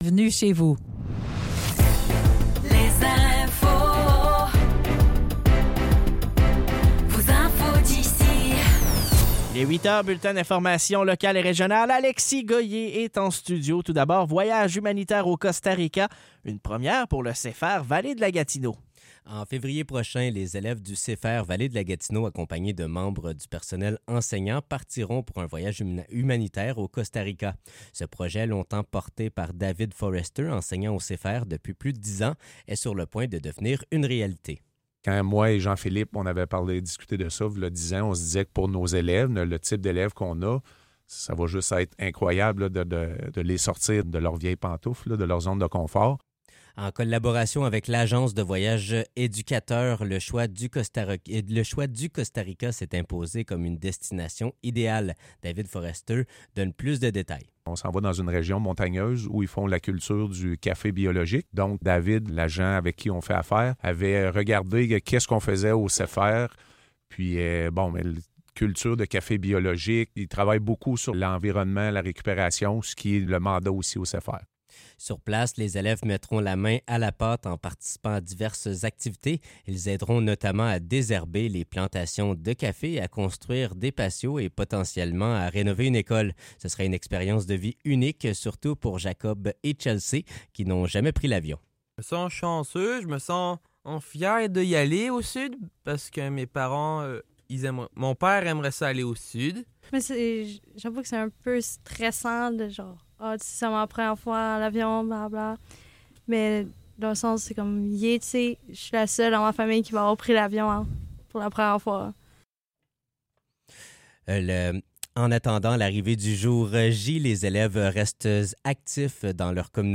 Nouvelles locales - 15 novembre 2024 - 8 h